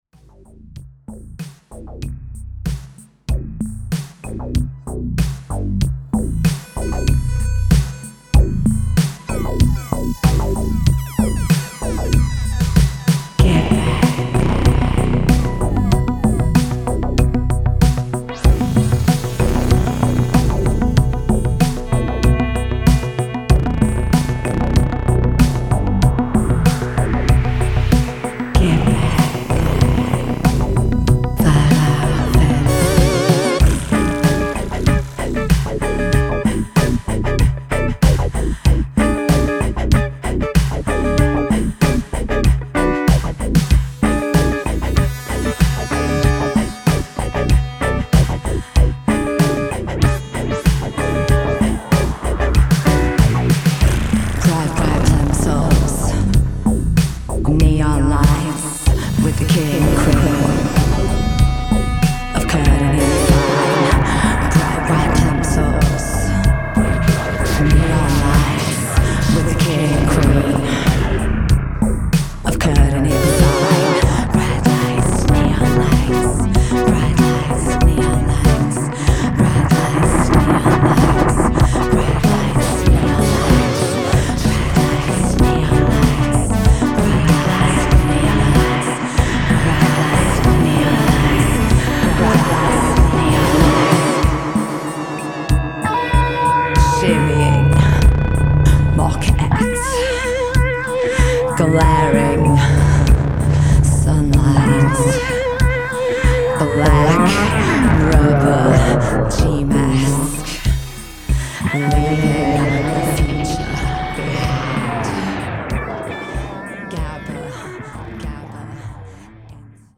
Wave Italo